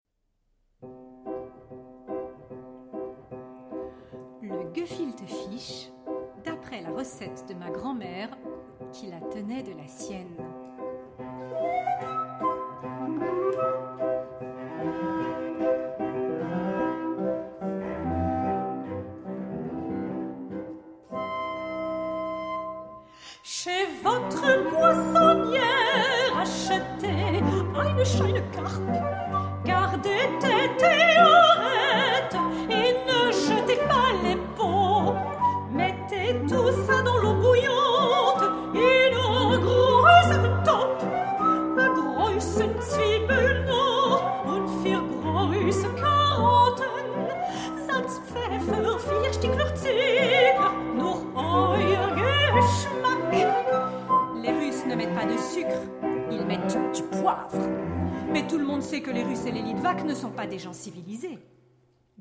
Une fiction musicale entrainante
soprano
pianoforte
clarinette
violoncelle
flûte